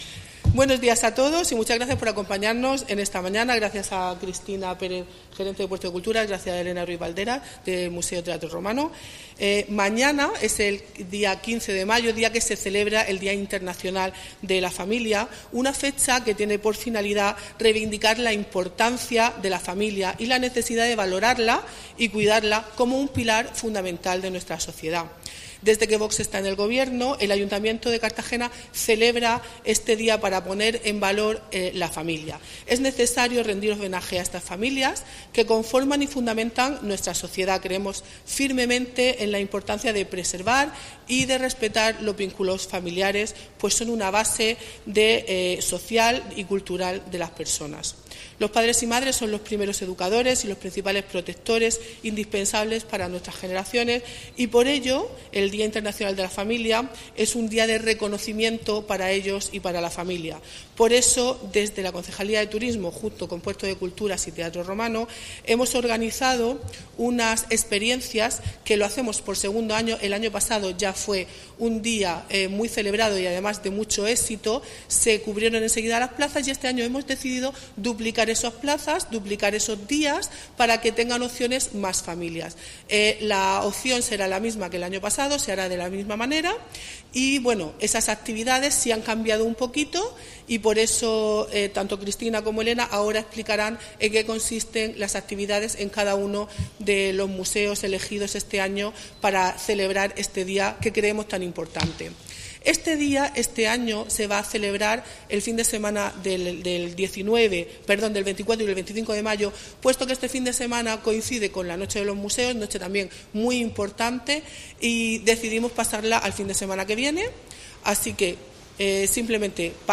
Enlace a Presentación actividades turísticas por el Día Internacional de la Familia